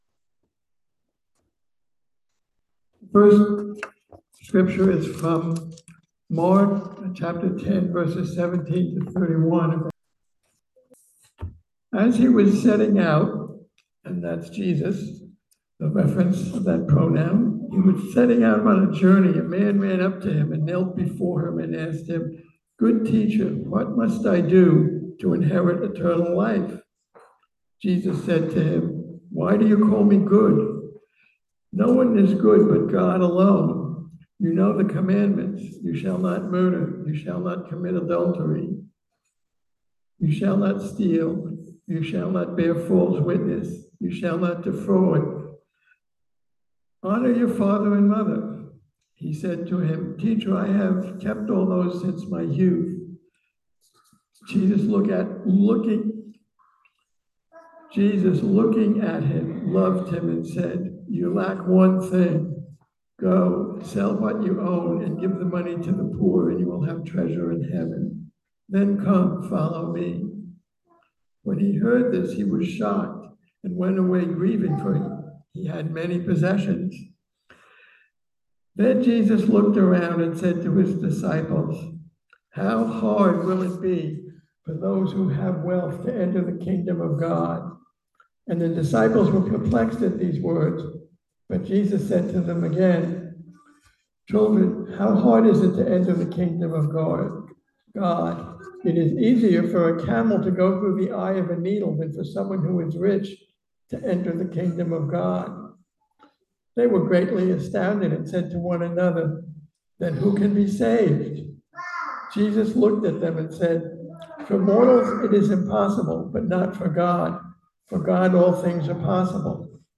Listen to the most recent message from Sunday worship at Berkeley Friends Church, “You Lack One Thing.”